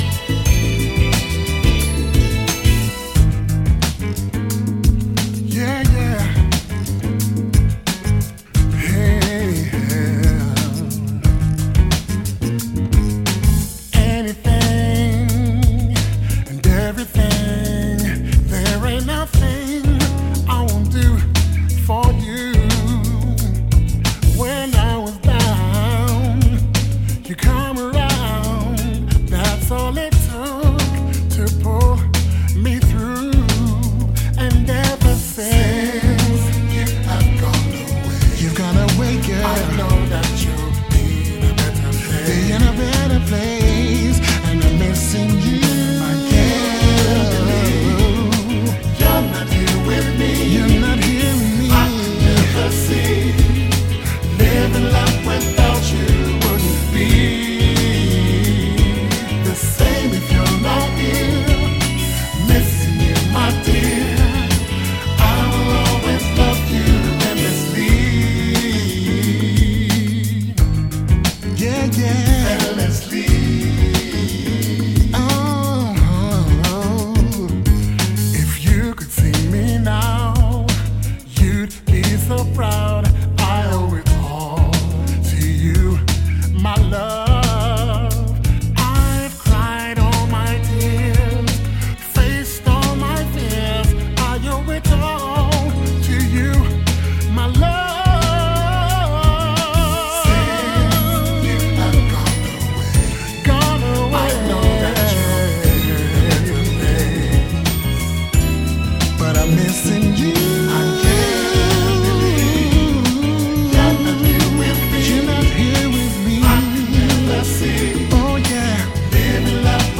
Urban Contemporary ~ Neo-Soul Featured Edition!